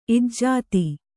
♪ ijjāti